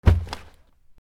カバンを落とす(mix用素材)
/ J｜フォーリー(布ずれ・動作) / J-10 ｜転ぶ　落ちる
服の上